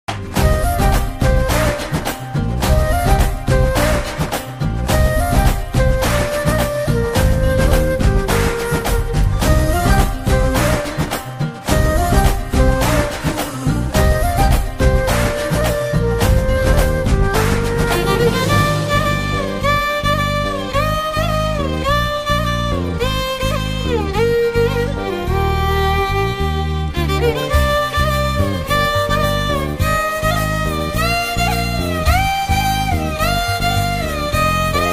Tamil Ringtones